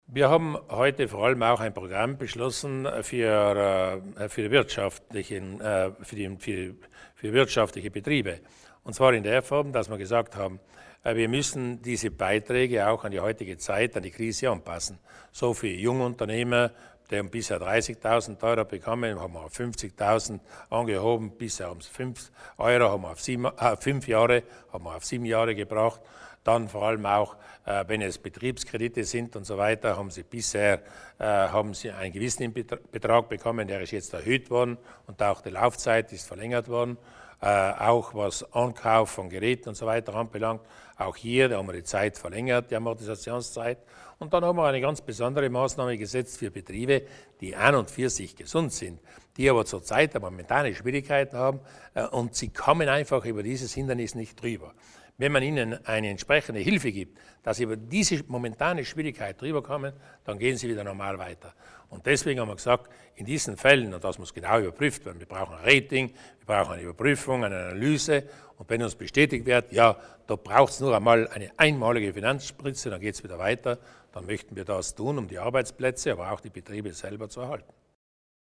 Landeshauptmann Durnwalder erläutert die Maßnahmen für kleine und mittlere Betriebe